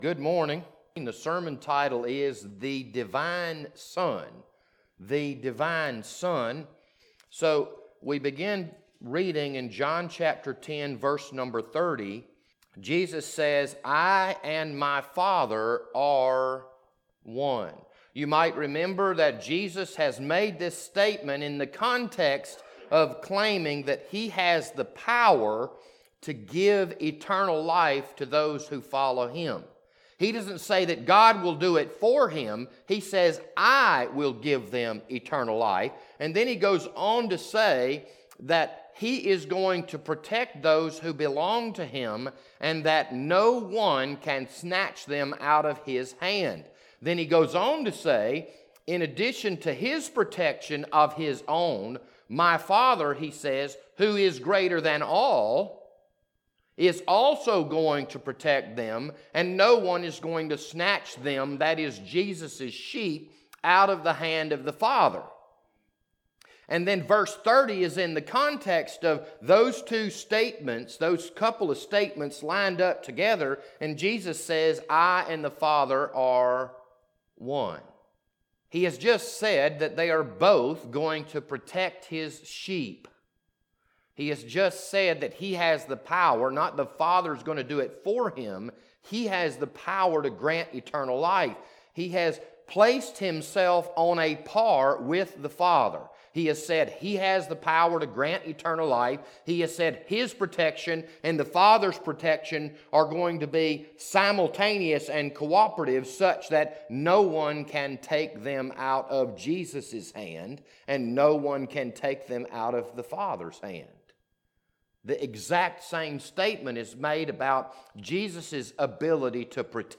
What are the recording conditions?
This Sunday morning sermon was recorded on August 2nd, 2020.